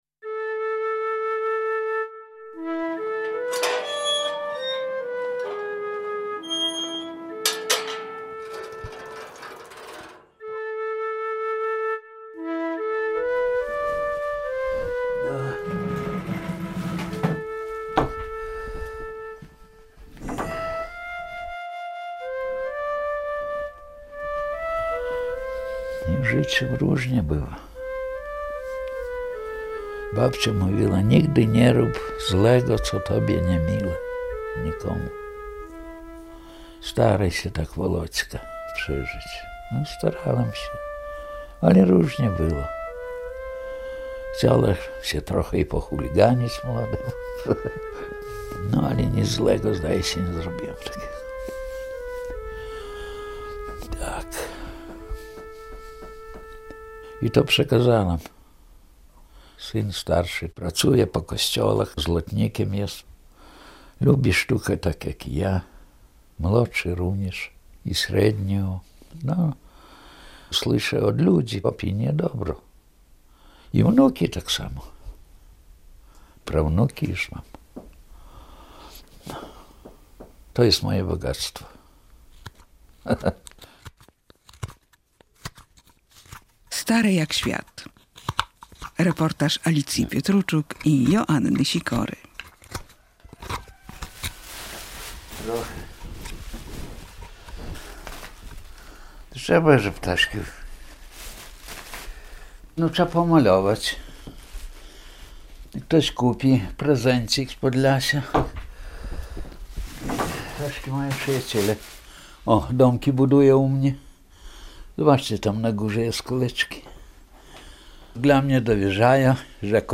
Radio Białystok | Reportaż